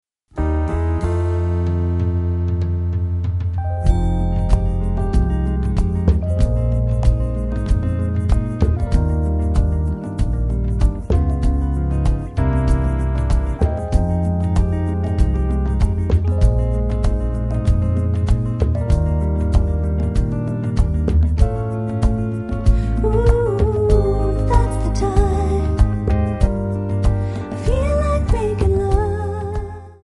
MPEG 1 Layer 3 (Stereo)
Backing track Karaoke
Pop, Oldies, 1960s